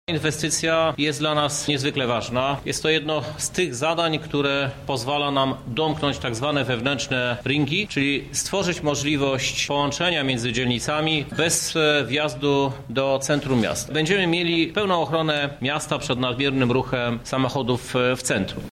Jak ważne jest to dla naszego miasta podkreślał Prezydent Krzysztof Żuk: